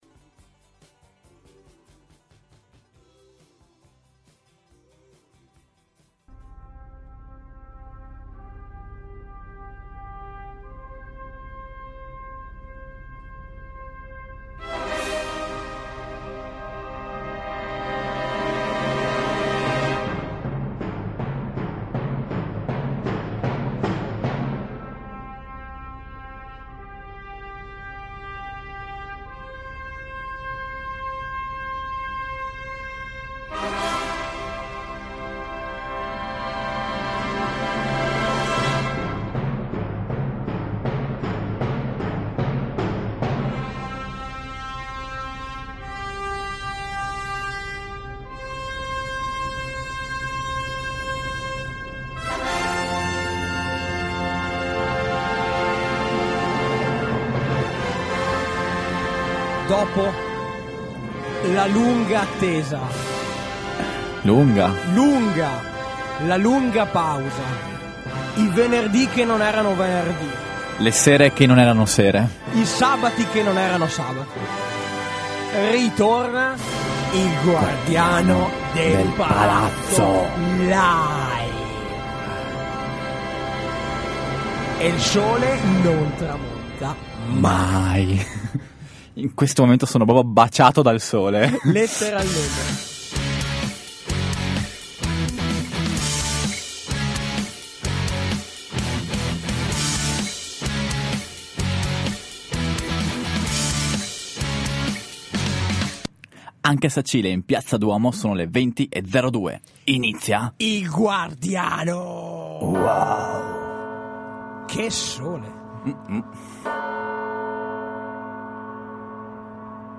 Programma con musiche ed artisti locali live